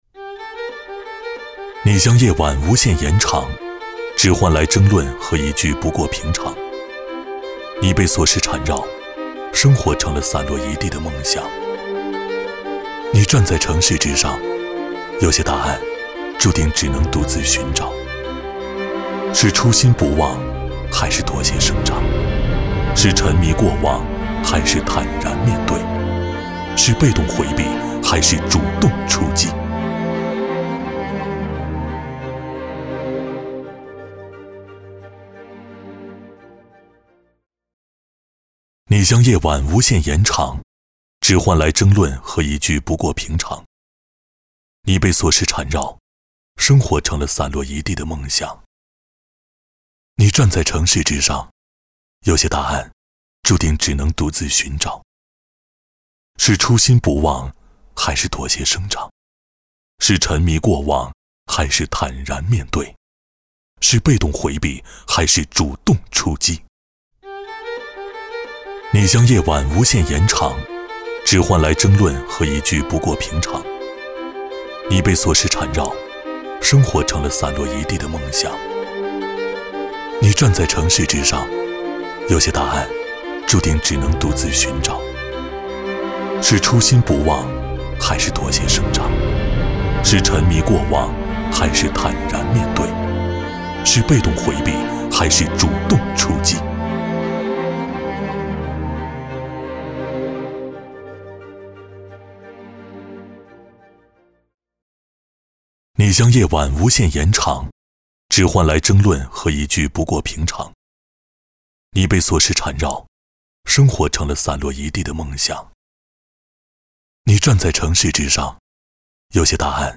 国语青年大气浑厚磁性 、沉稳 、男专题片 、宣传片 、60元/分钟男S310 国语 男声 宣传片-我们的旅程-自然、深情 大气浑厚磁性|沉稳 - 样音试听_配音价格_找配音 - voice666配音网